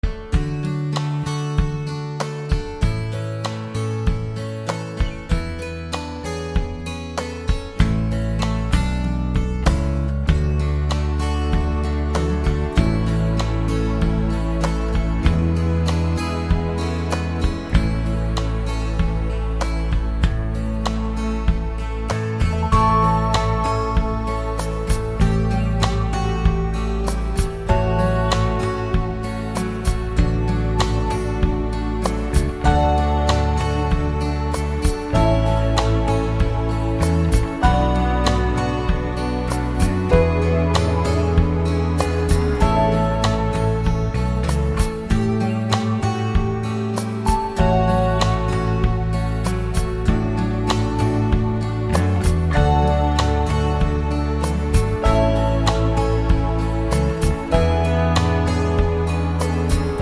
no Backing Vocals Pop (1970s) 3:59 Buy £1.50